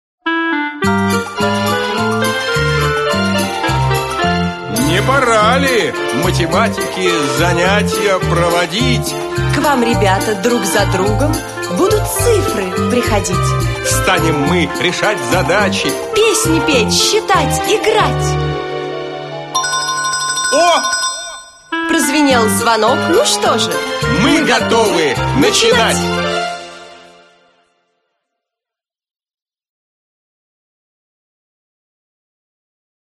Аудиокнига Цифры будем изучать – один, два, три, четыре, пять | Библиотека аудиокниг